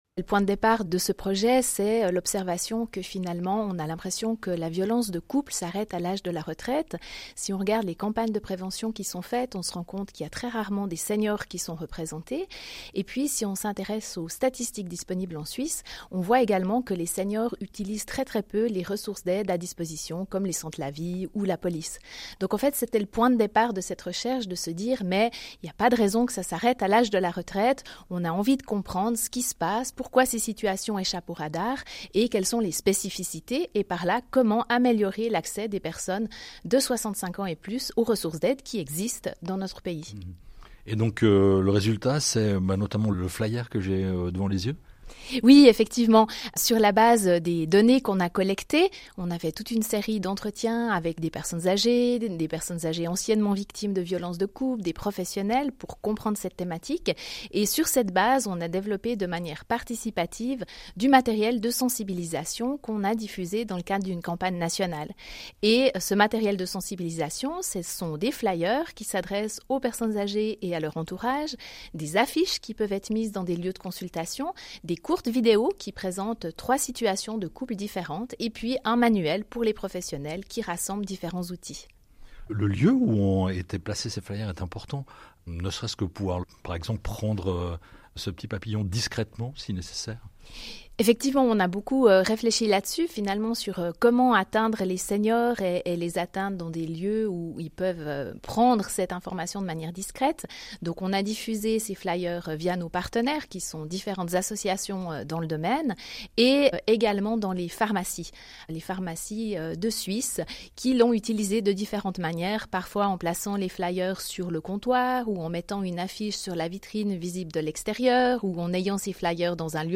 Les membres du groupe du pilotage du senior-lab étaient à l’antenne de l’émission CQFD de la RTS en décembre 2024 pour présenter trois projets pilotés par les trois hautes écoles fondatrices de la plateforme.